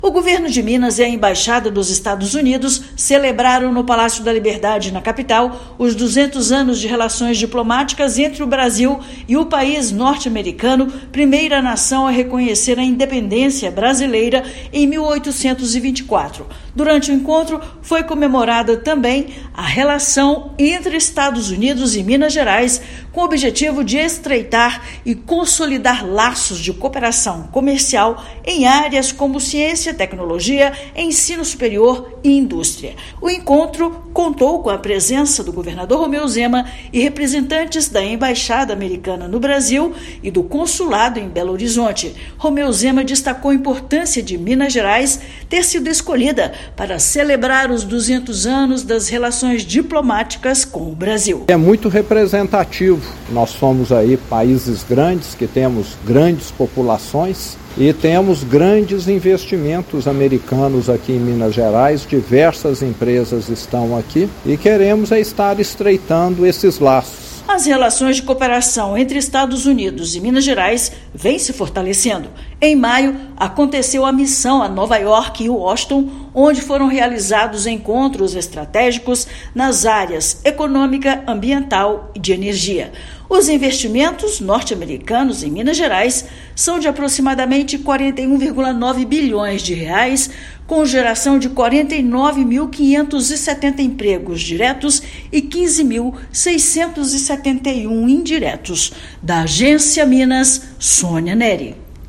Encontro no Palácio da Liberdade marca estreitamento dos laços comerciais e de cooperação entre Minas e Estados Unidos. Ouça matéria de rádio.